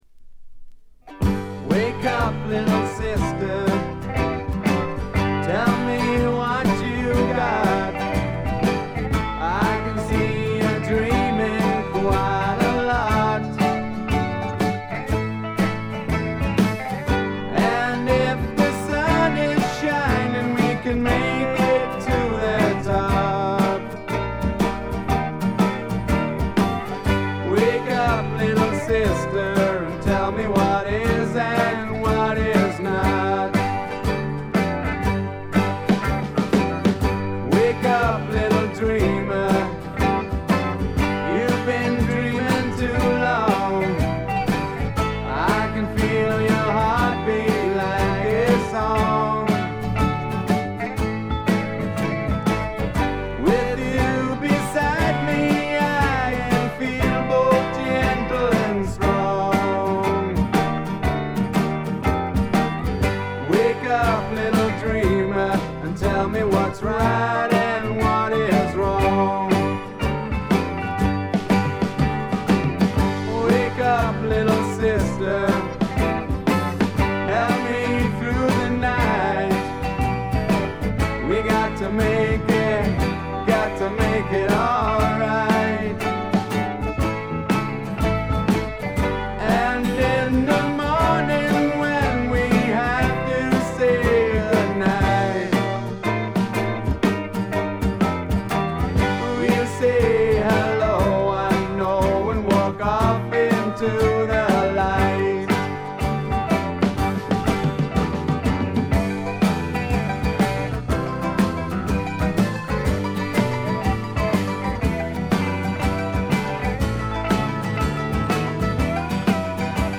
ほとんどノイズ感無し。
これぞ英国流フォークロックとも言うべき名作です。
試聴曲は現品からの取り込み音源です。